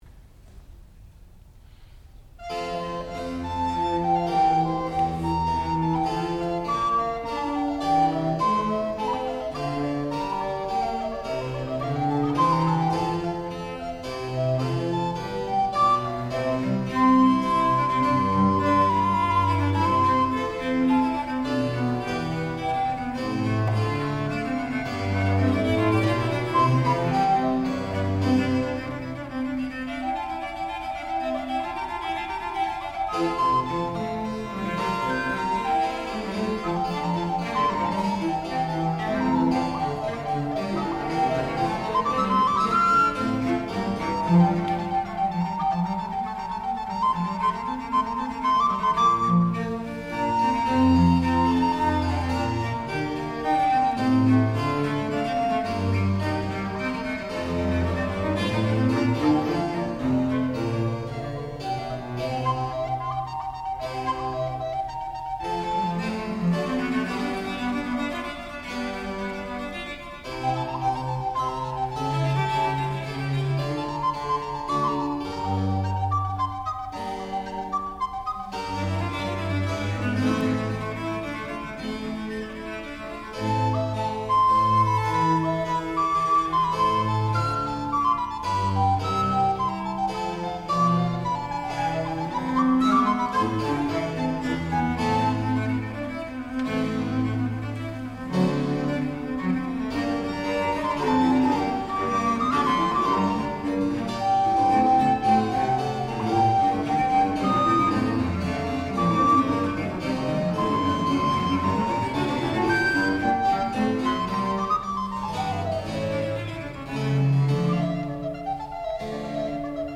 sound recording-musical
classical music
violoncello
harpsichord
viola da gamba
recorder